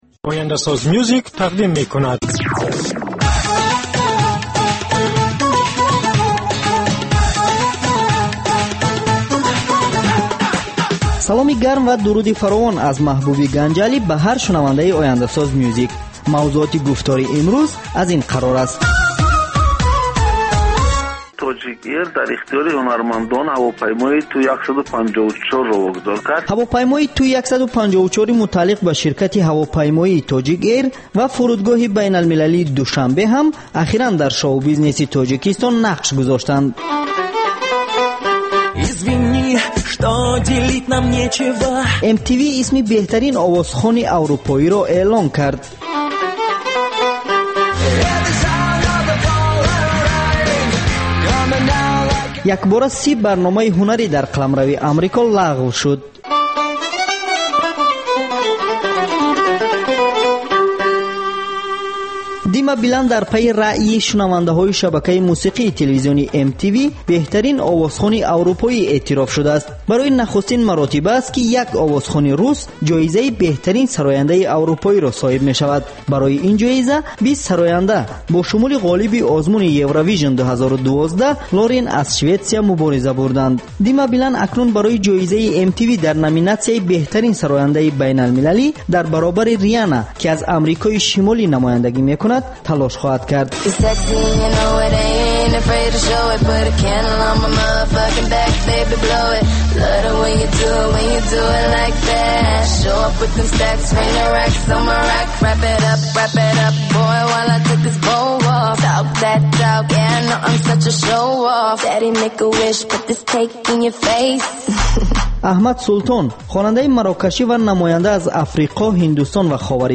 Навгониҳои мусиқӣ, беҳтарин оҳангҳо, гуфтугӯ бо оҳангсозон, овозхонон ва бинандагон, гузориш аз консертҳо ва маҳфилҳои ҳунарӣ.